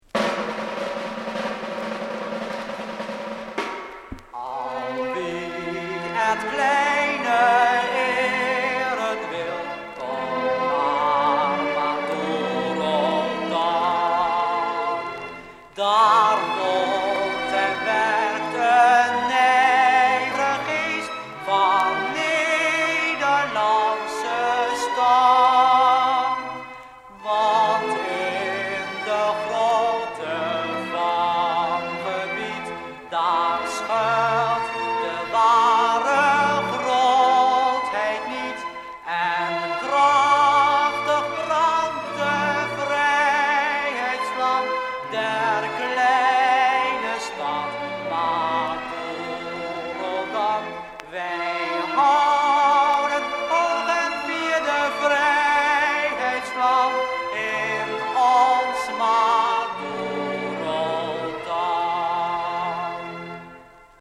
Liedjes